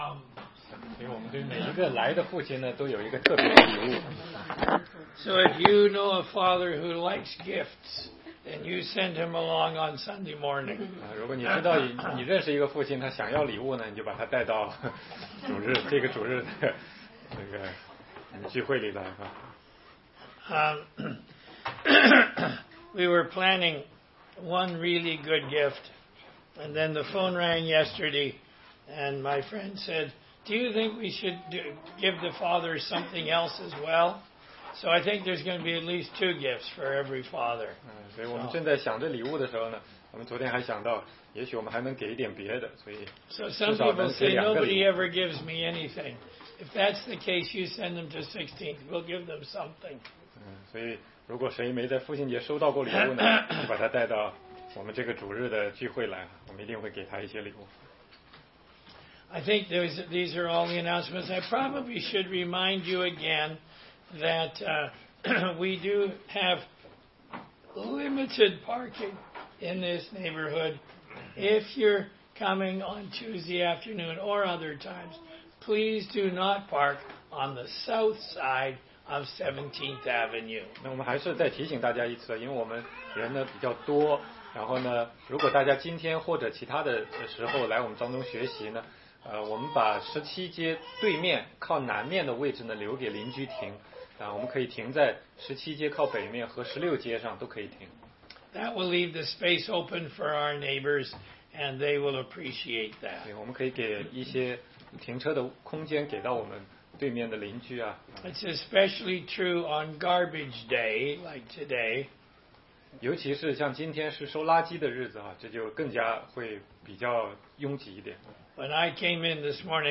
16街讲道录音 - 罗马书